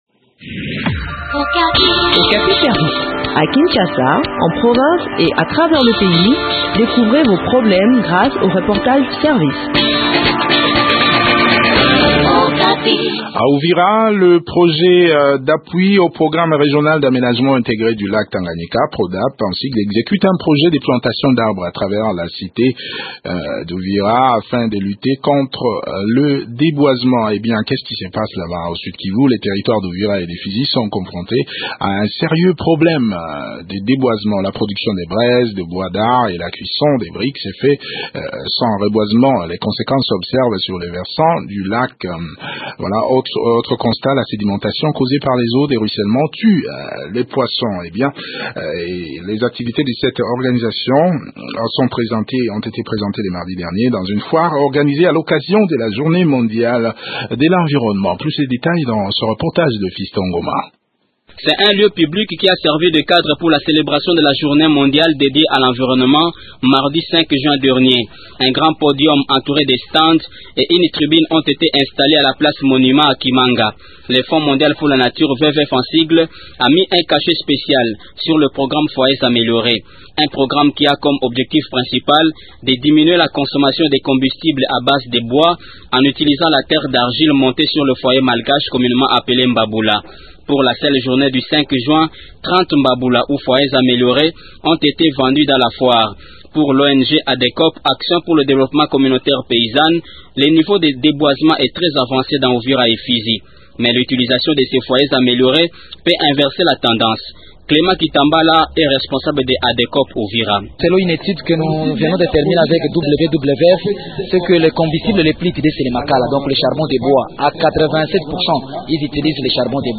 fait le point sur ce projet au micro